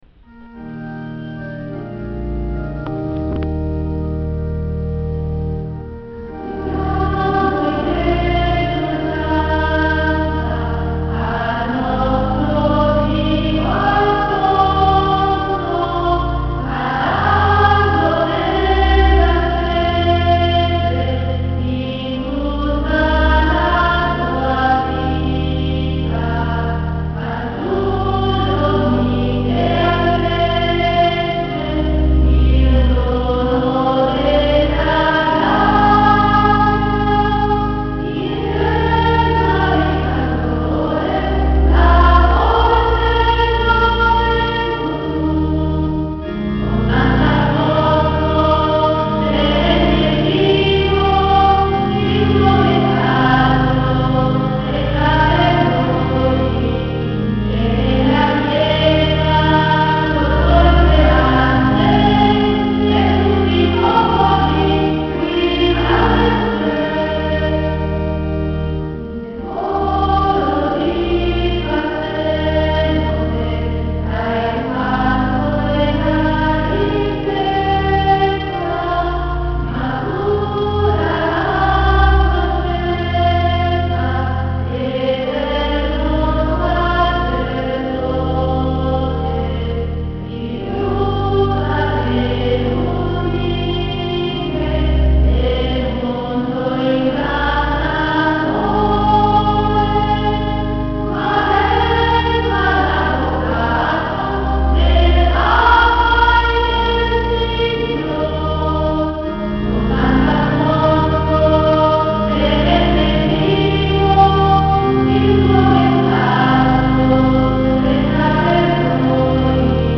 L’intero fascicolo è scaricabile in formato pdf; i testi dei singoli canti in formato *.txt. Anche le registrazioni in mp3 hanno valore di demo, cioè un aiuto ad imparare il canto. Le registrazioni sono state fatte qualche anno fa dalle Monache Redentoriste di Scala (purtroppo, non di tutti i canti).